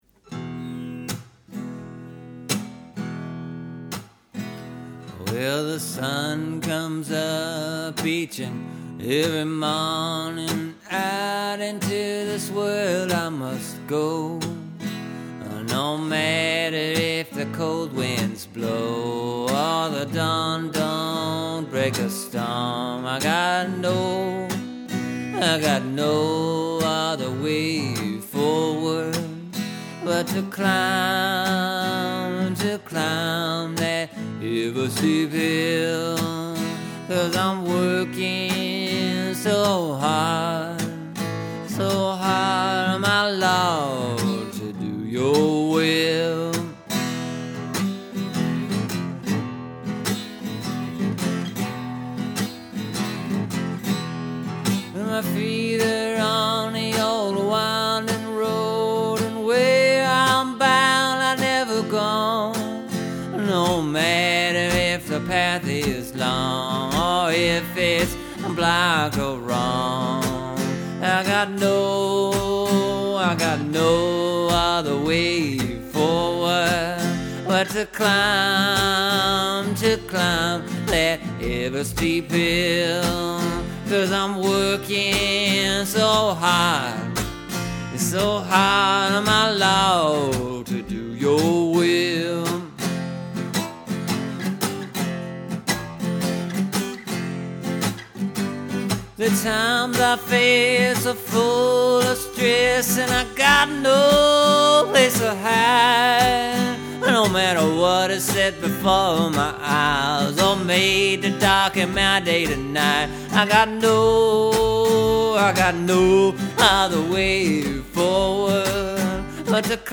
Here’s the idea: Do some key changes in this song.